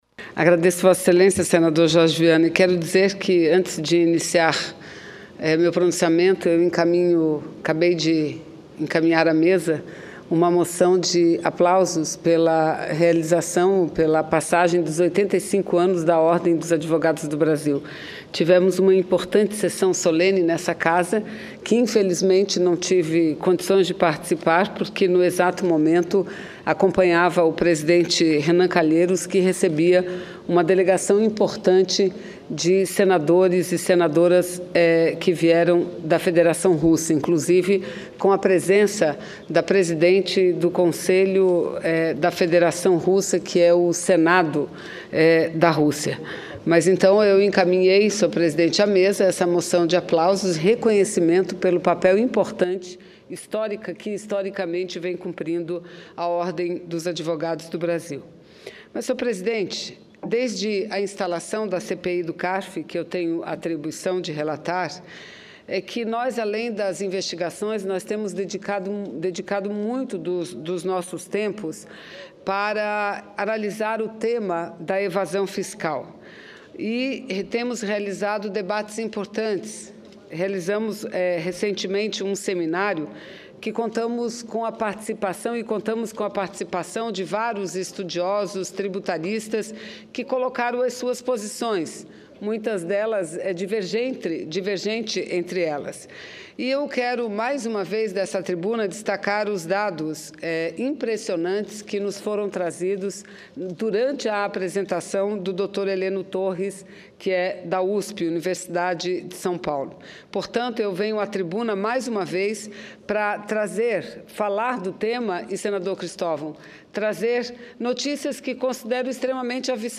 Discurso